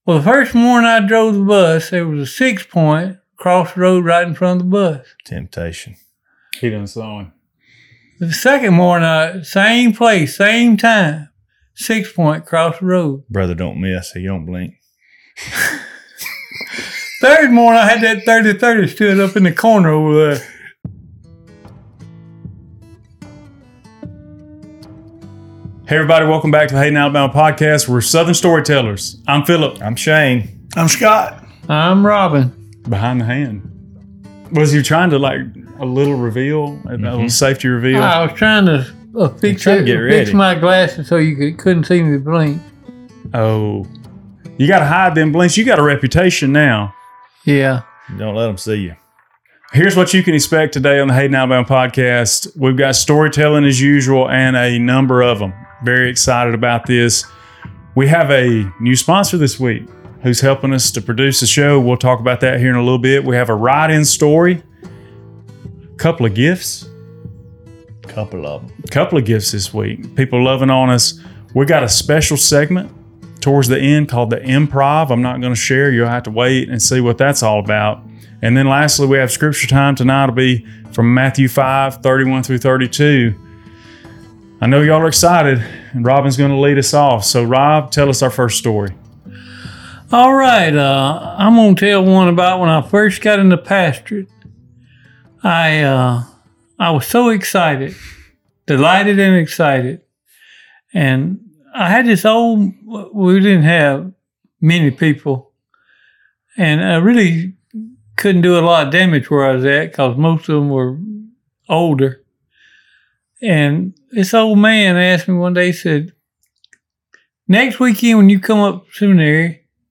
This episode contains storytelling, a write-in story, gift openings, an improv game, and a new product that you need to know about.